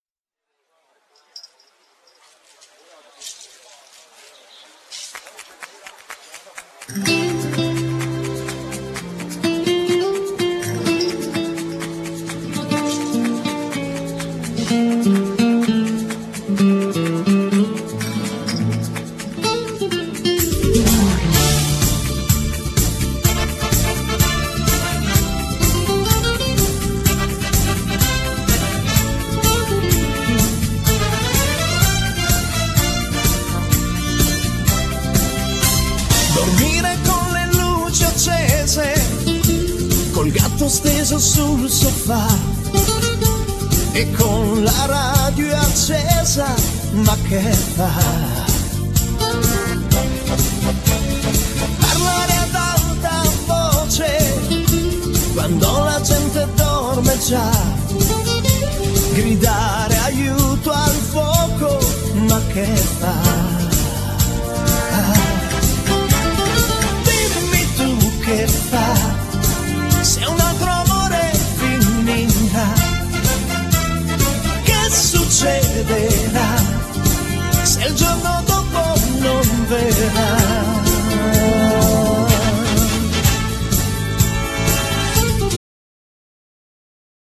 Genere : Pop / folk